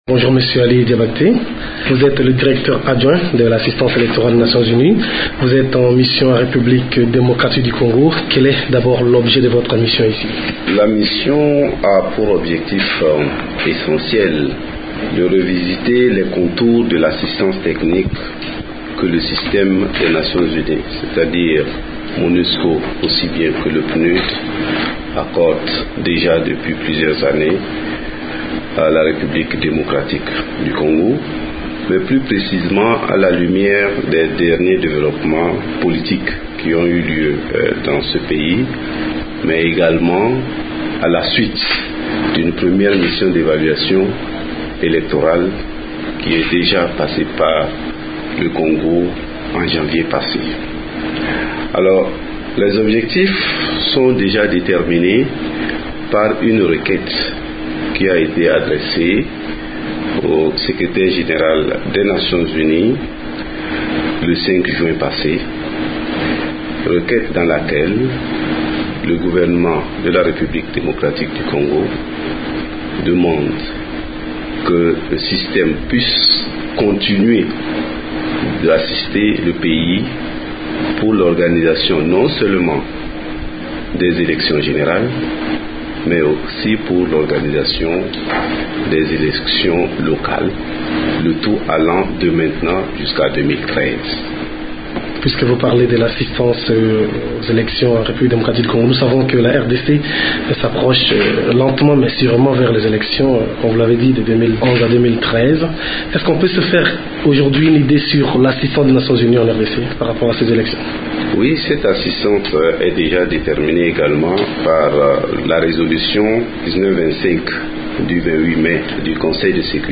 répond aux questions de